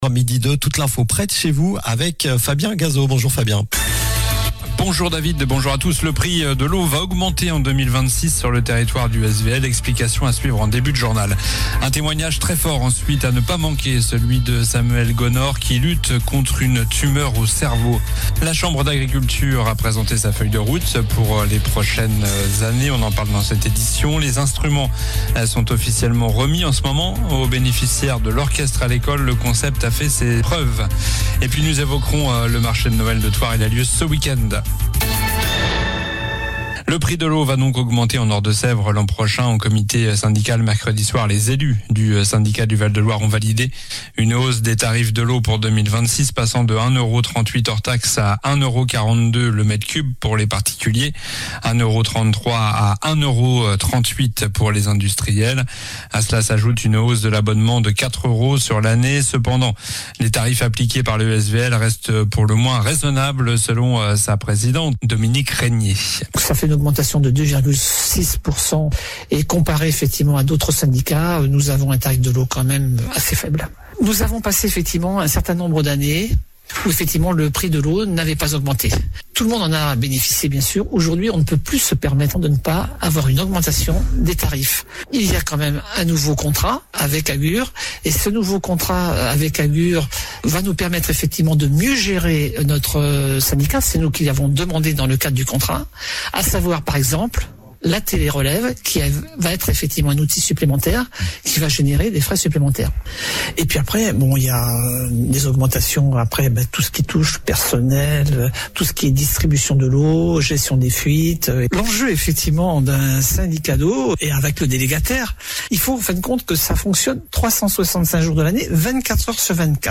Journal du vendredi 12 décembre (midi)